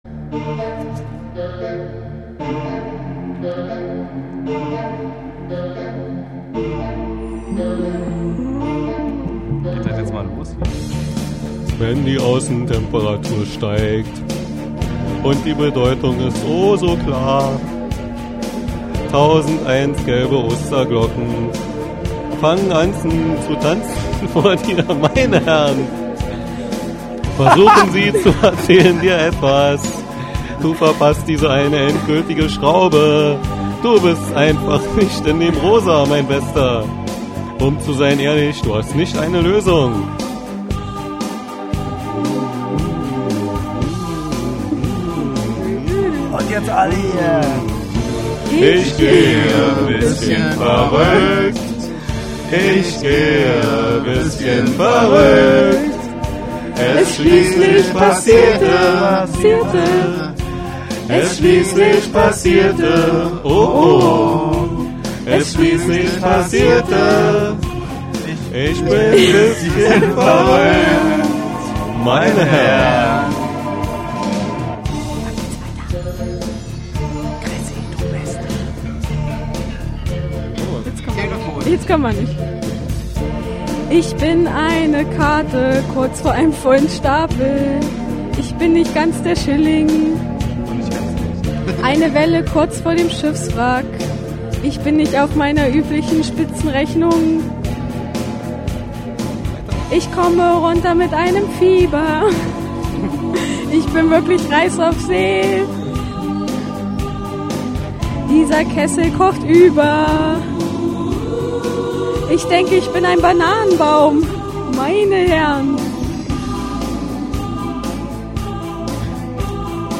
Uraufführung
die sangesbrüder am tisch